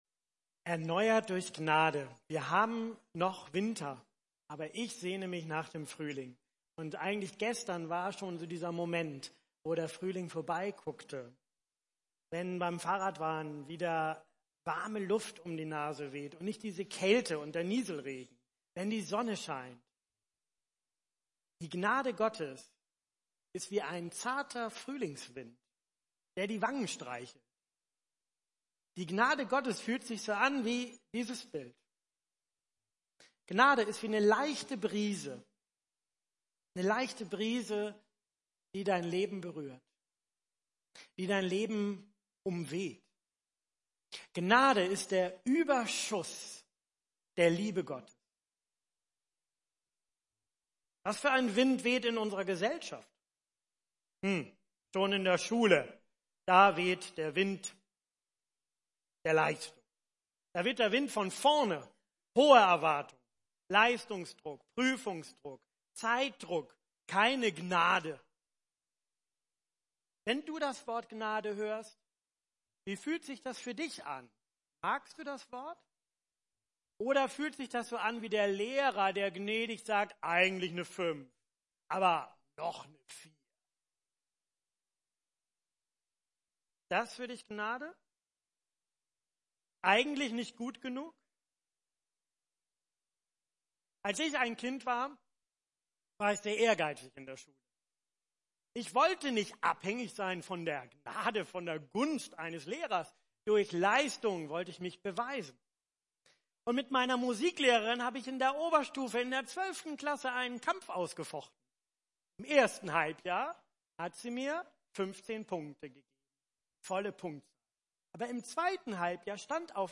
Predigttext: Offenbarung 3, 17.20